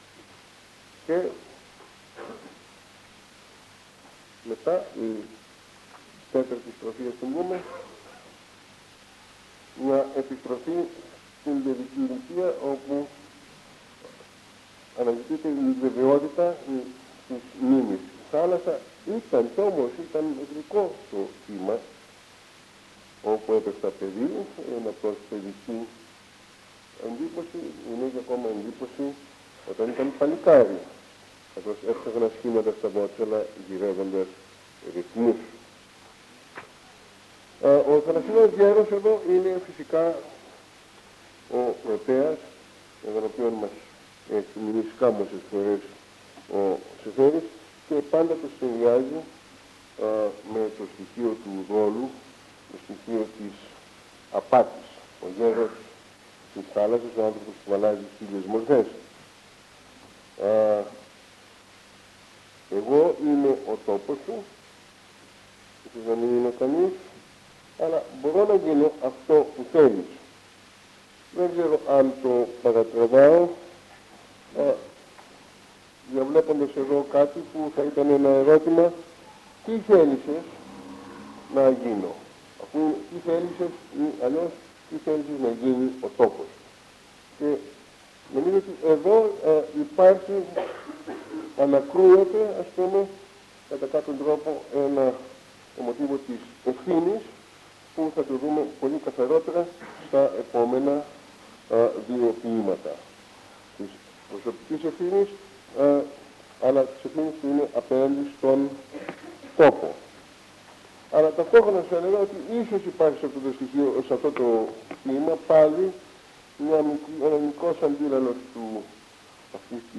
Εξειδίκευση τύπου : Εκδήλωση
Περιγραφή: Κύκλος Μαθημάτων